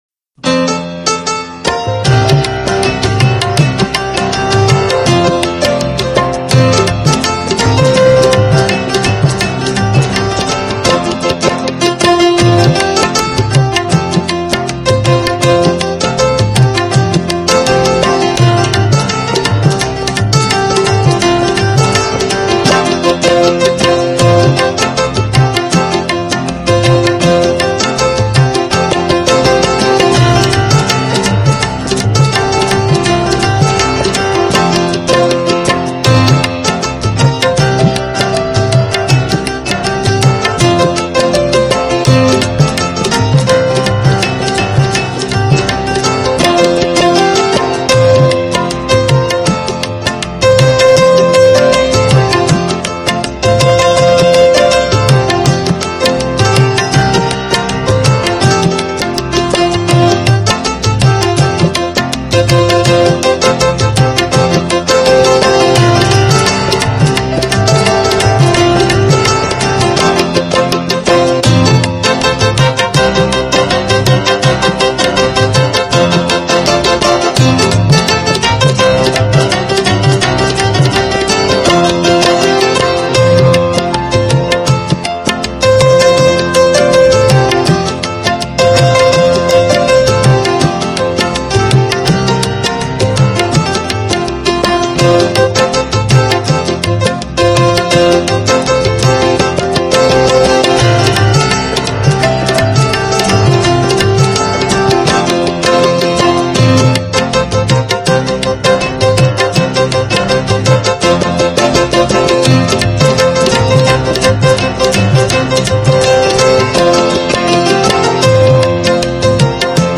HomeMp3 Audio Songs > Instrumental Songs > Old Bollywood